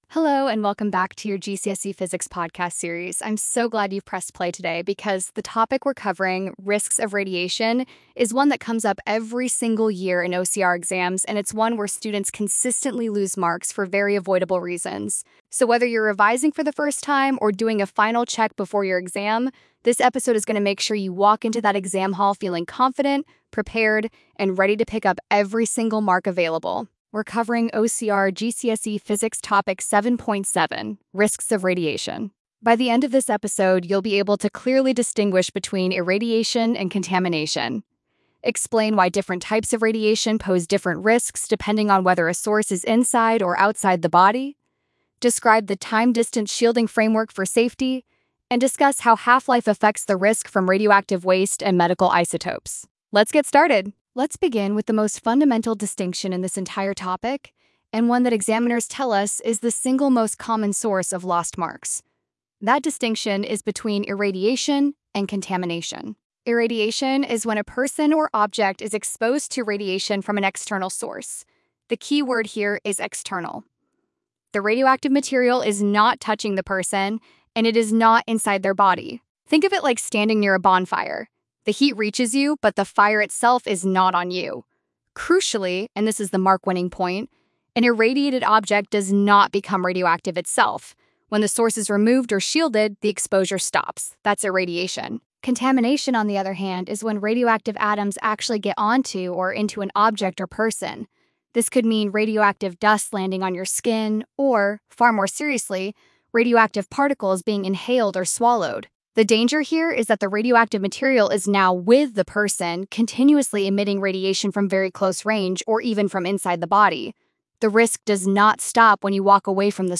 🎙 Podcast Episode Risks of Radiation 10:42 0:00 -10:42 1x Show Transcript Study Notes Overview Welcome to your deep dive into the Risks of Radiation (OCR GCSE Physics, 7.7).